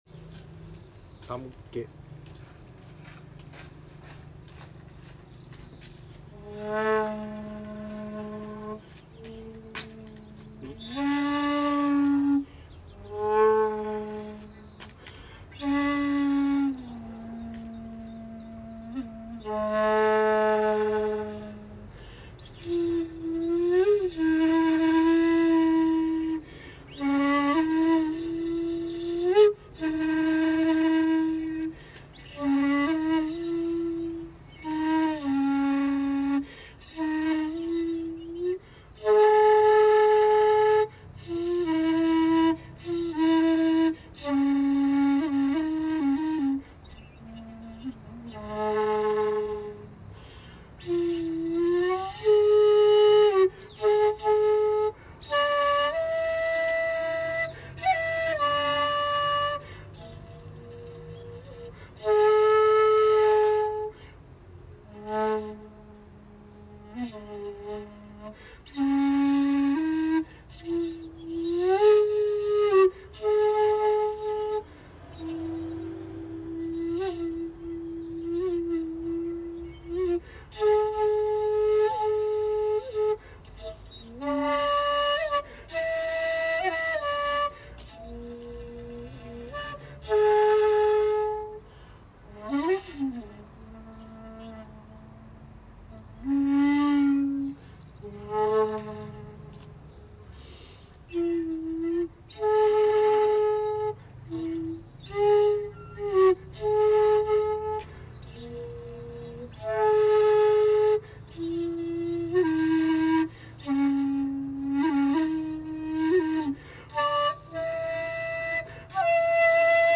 （写真①：善通寺境内にて尺八）
（音源：尺八吹奏「手向」
515-善通寺児童公園.mp3